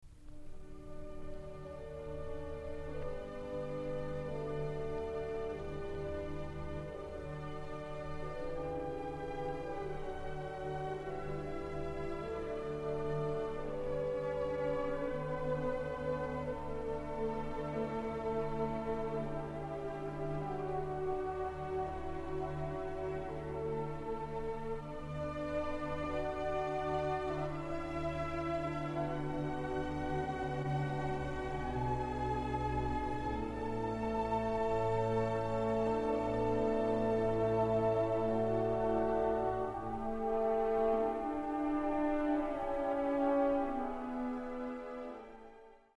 Классическая музыка
Классическая музыка И.Брамс. Концерт № 1 для фортепиано с оркестром ре минор, соч. 15 / Эмиль Гилельс. Берлинский филармонический оркестр, дир. Эуген Йохум 1.